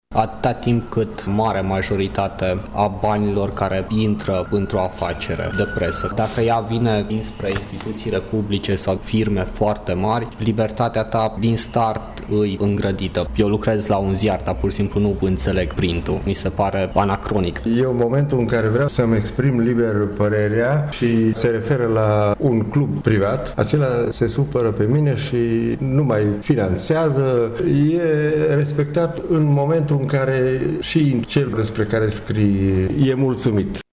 Aceasta este părerea cetățenilor și a unor oameni de presă din Tîrgu-Mureș, surprinsă astăzi, cu prilejul Zilei Mondiale a Libertăţii Presei.